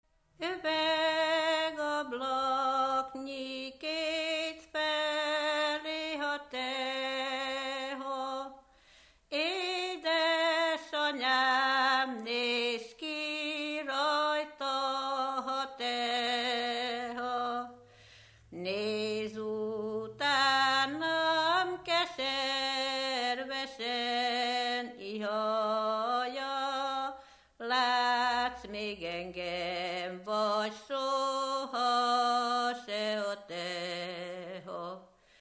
Erdély - Kolozs vm. - Váralmás
Stílus: 4. Sirató stílusú dallamok
Kadencia: 5 (4) 5 1